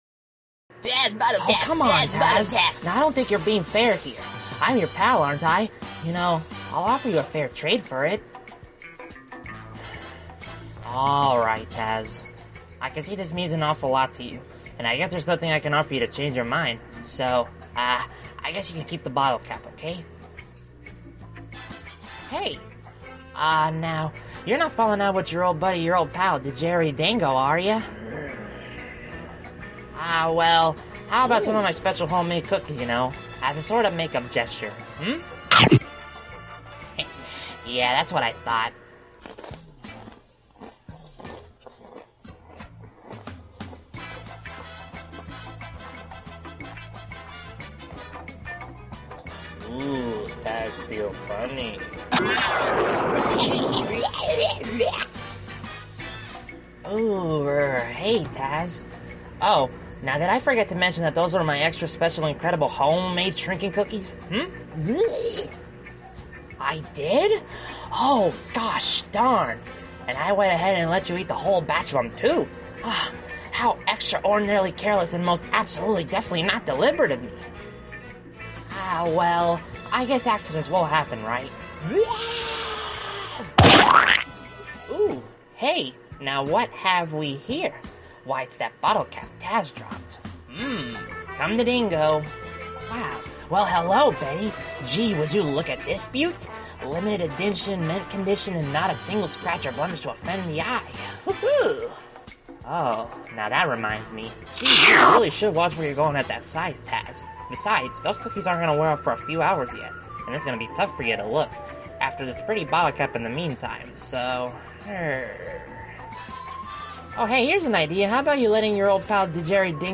The Cunning Cookie Caper Comic Dub
But anyway asides that yes I voiced both Digeri and Taz, since it's only two characters.
Sorry if my Taz voice ain't very good!